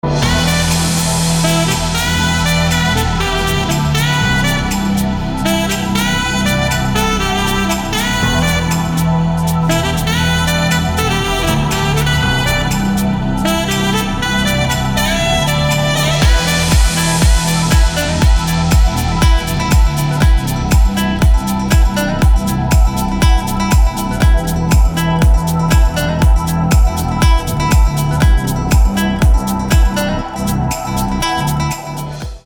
• Качество: 320, Stereo
громкие
красивые
dance
без слов
Downtempo
инструментальные
Саксофон